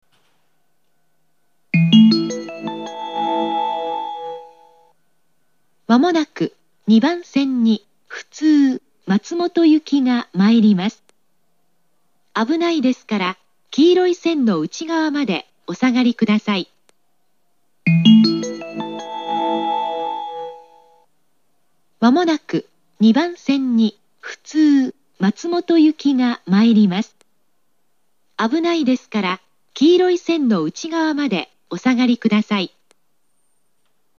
２番線通過列車接近放送
nishi-hachioji2bansen-sekkin.mp3